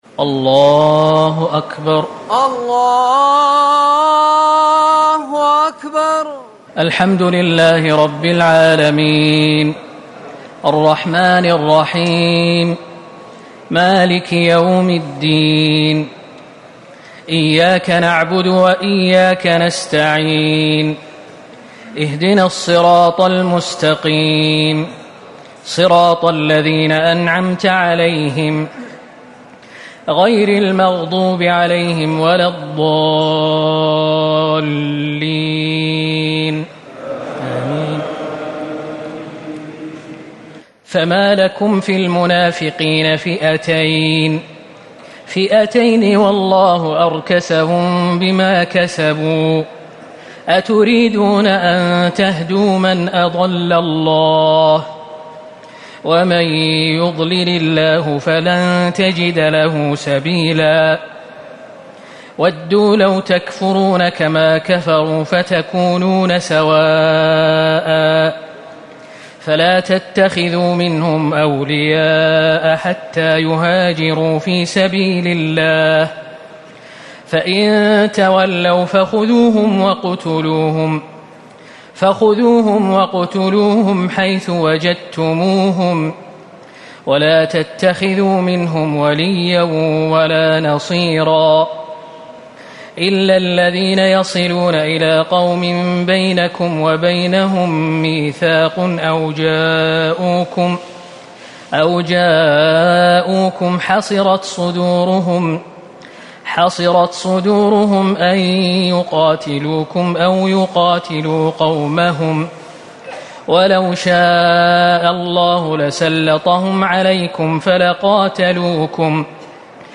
ليلة ٥ رمضان ١٤٤٠ من سورة النساء ٨٨-١٦٢ > تراويح الحرم النبوي عام 1440 🕌 > التراويح - تلاوات الحرمين